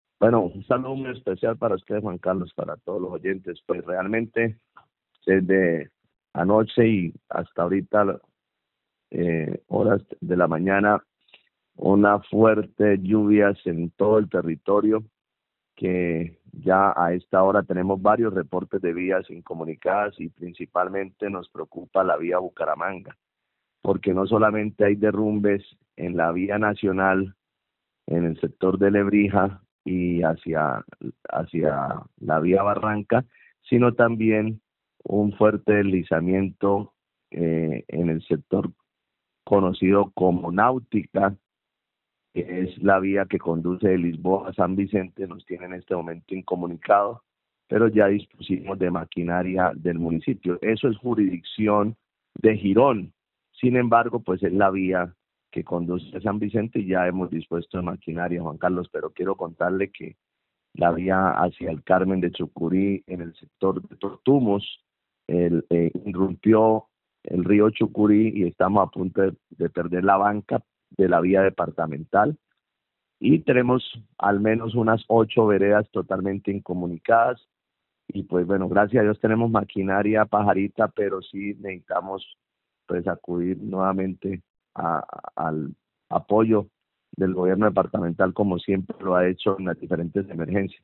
Óscar Sanmiguel, alcalde de San Vicente de Chucurí